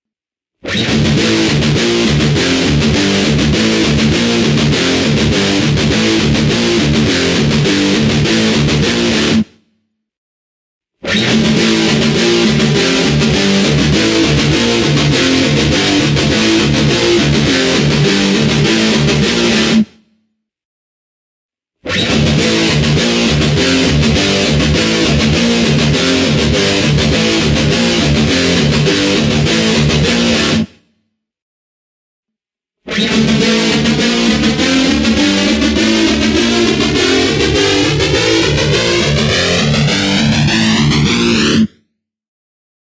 大声重摇滚吉他，（现在没有电源嗡嗡声）
重金属 捶打 扭曲 处理 切丝 吉他 失真 金属